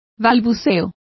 Complete with pronunciation of the translation of prattle.